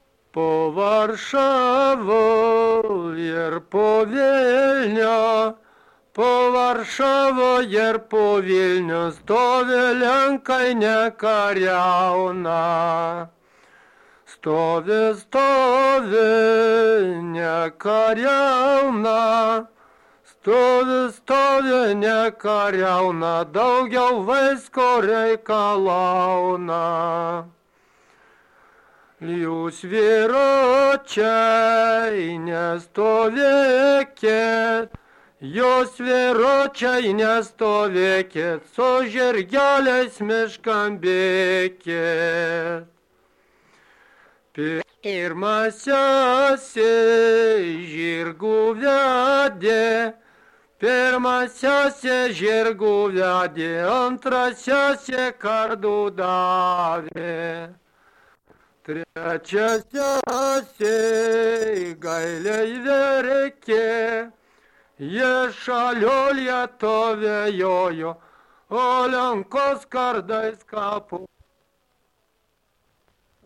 Dalykas, tema daina
Erdvinė aprėptis Barčiai (Varėna)
Atlikimo pubūdis vokalinis